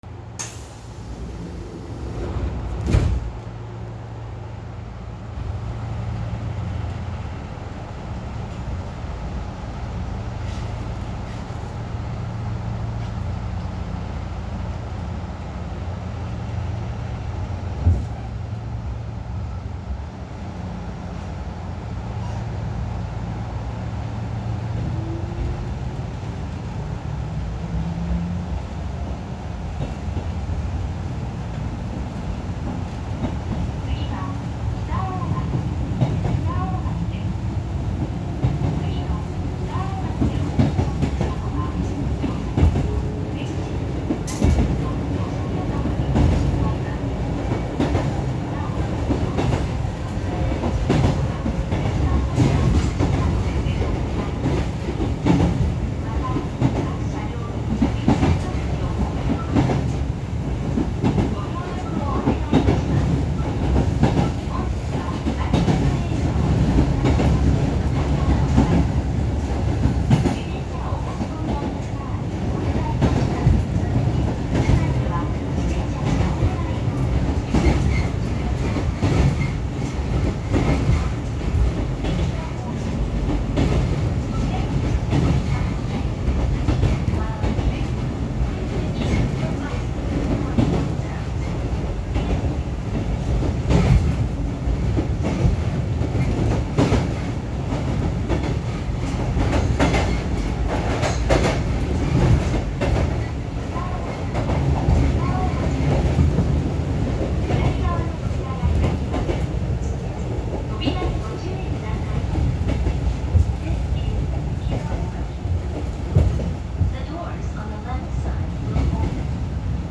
〜車両の音〜
・600系統走行音
【養老線】室→北大垣（2分20秒：4.28MB）…収録はD06(606F)にて。
ごく普通の抵抗制御となります。ドアブザーと自動放送を搭載。
506_Muro-KitaOgaki.mp3